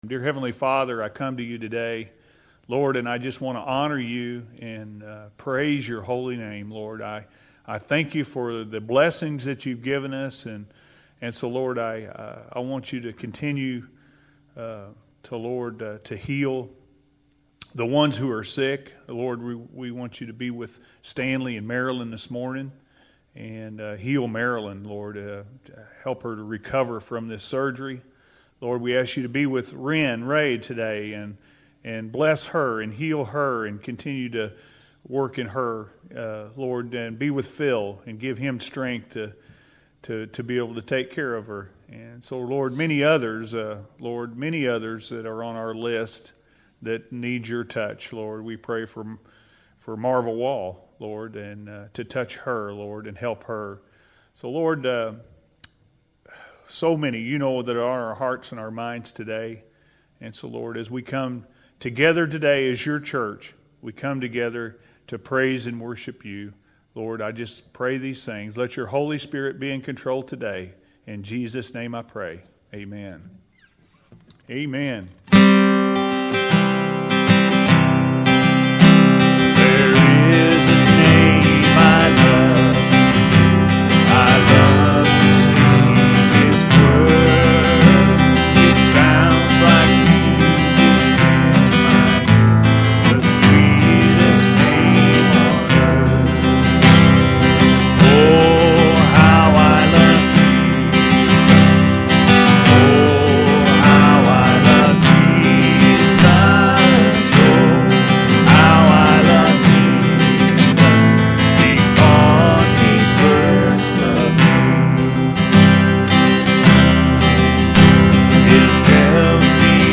True Love-A.M. Service